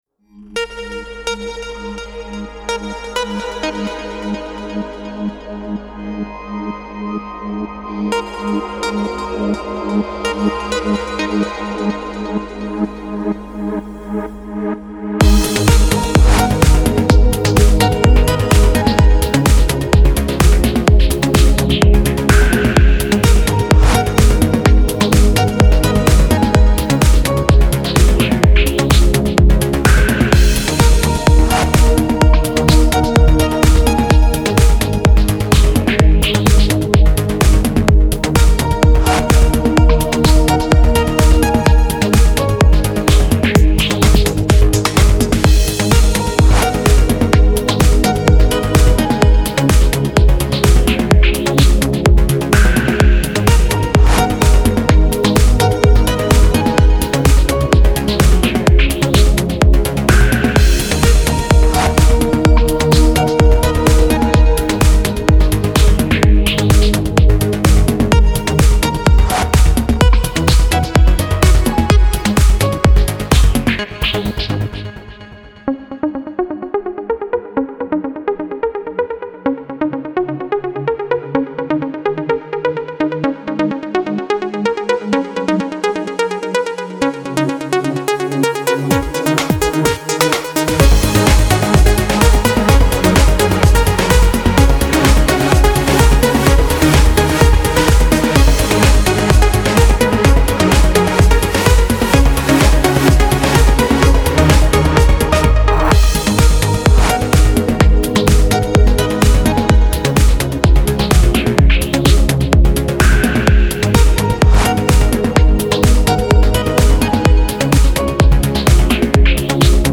Genre: Synthpop Synthwave.